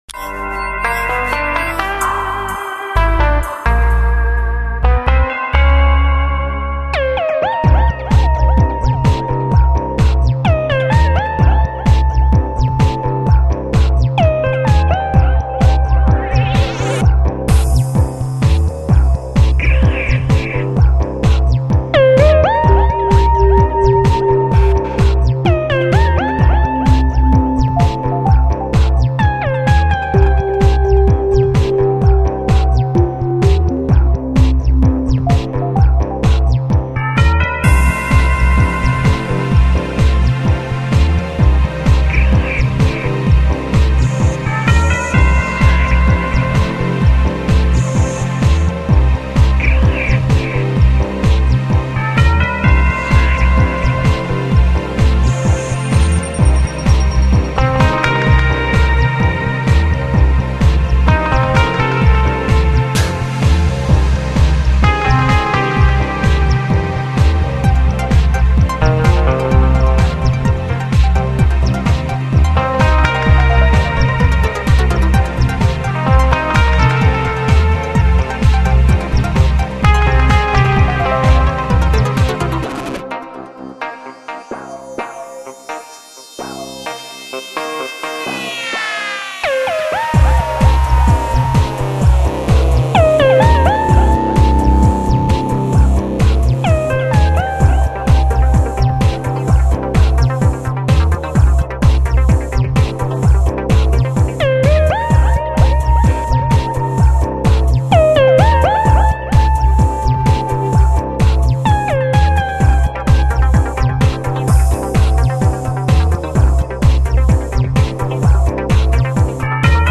作为世界上第一张完全采用5.1声道录制的音乐专辑
因为是从5.1声道的录音缩混而成